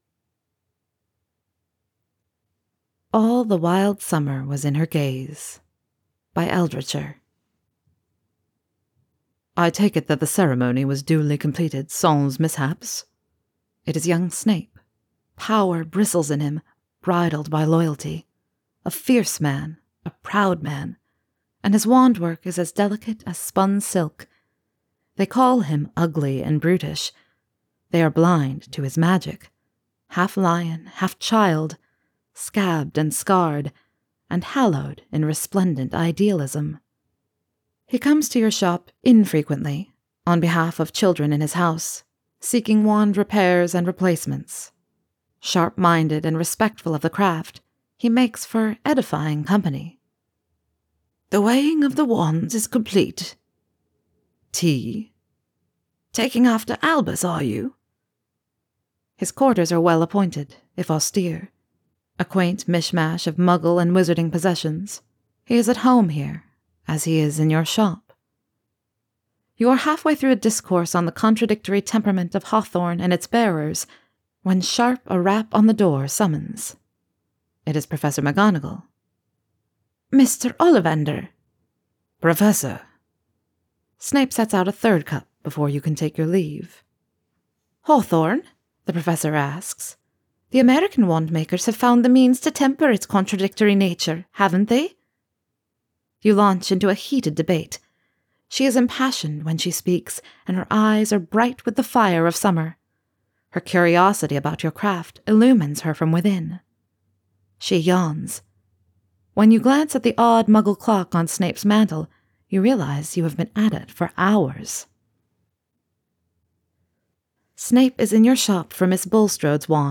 with music & effects: download mp3: here (r-click or press, and 'save link') [15 MB, 00:14:17]